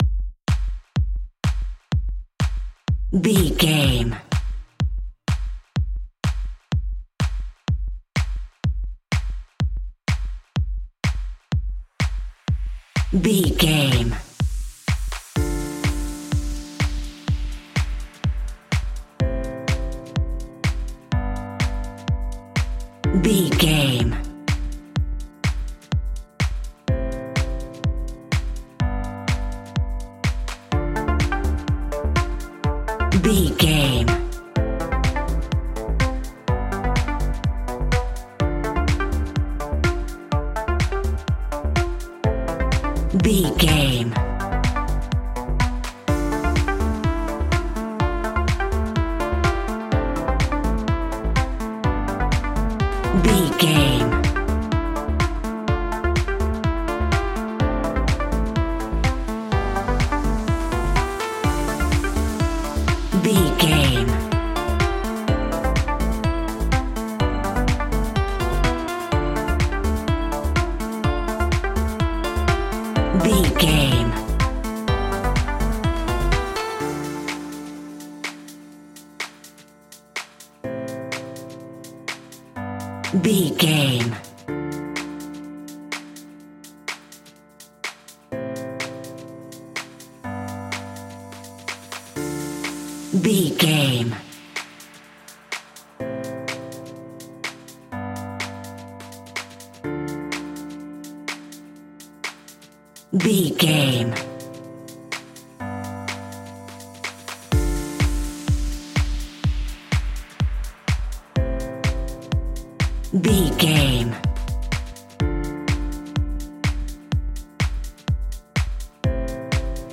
Aeolian/Minor
D
driving
energetic
uplifting
hypnotic
funky
groovy
drum machine
synthesiser
electro house
synth leads
synth bass